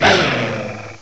cry_not_lycanroc.aif